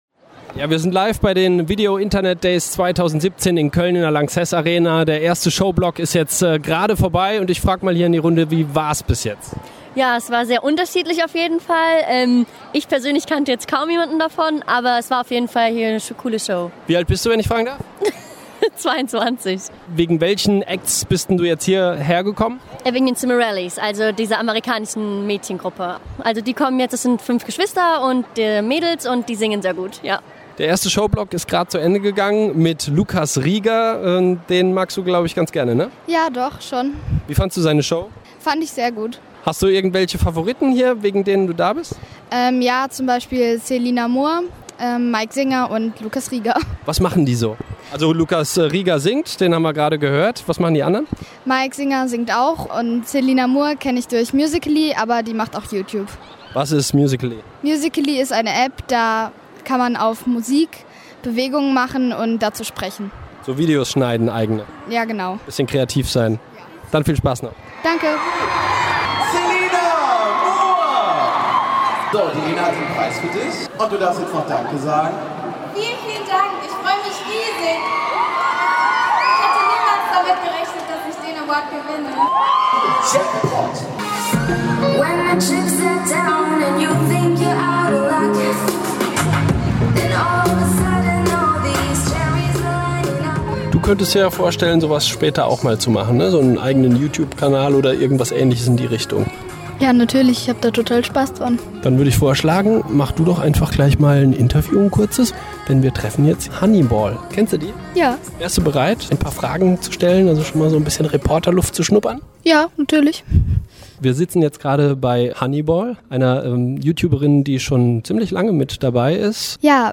Kreischalarm in Köln: VideoDays 2017
Gestern war in Köln Showtime und heute ging das Gekreische weiter, beim Meet & Greet mit den Internethelden der Kids.